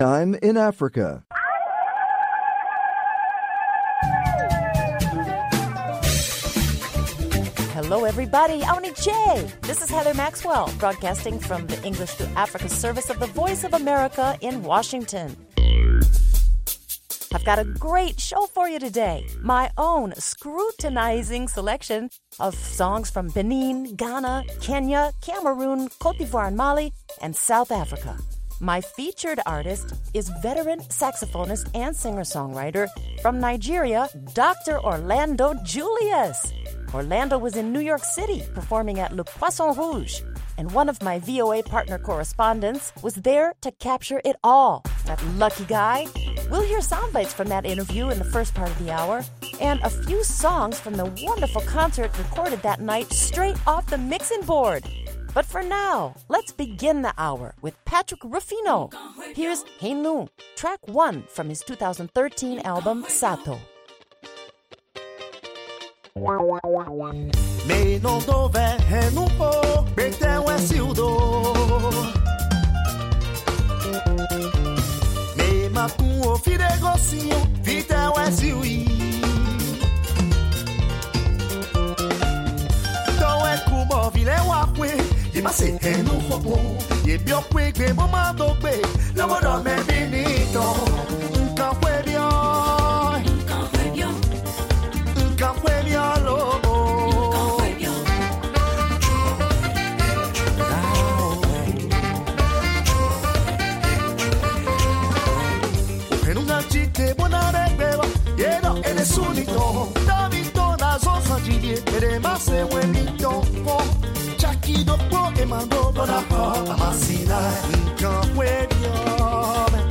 Music Time in Africa is VOA’s longest running English language program. Since 1965, this award-winning program has featured pan African music that spans all genres and generations.